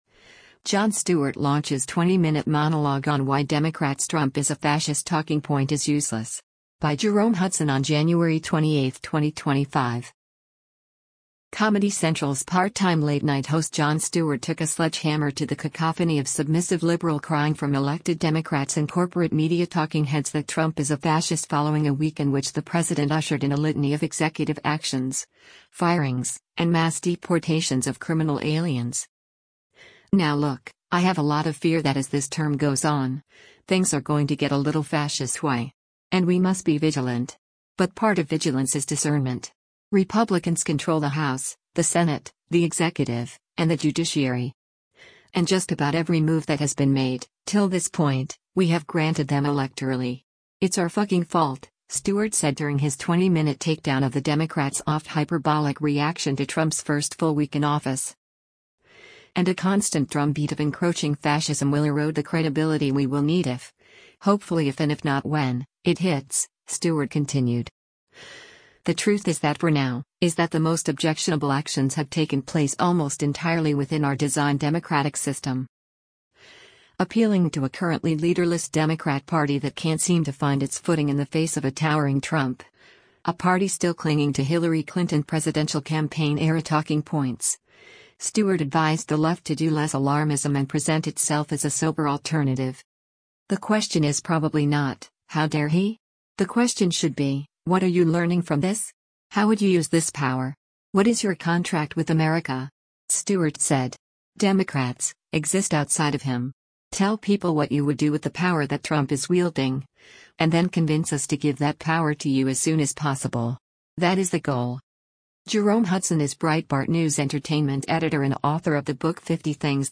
Jon Stewart Launches 20-Minute Monologue on Why Democrats' 'Trump Is a Fascist' Talking Point Is Useless